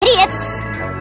lollipop-chansaw-welcome-to-my-bedroom-cutscene-mp3cut.mp3